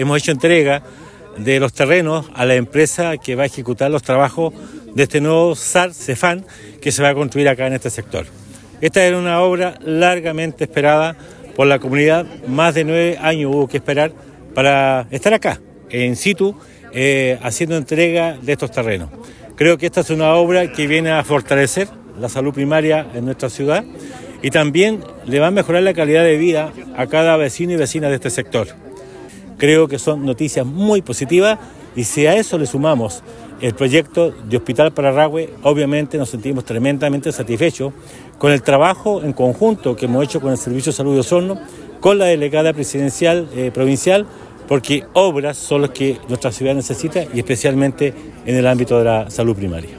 En tanto el Alcalde Emeterio Carrillo, señaló que esta era una obra esperada por la comunidad lo que fortalecerá el trabajo que realizan los equipos de salud.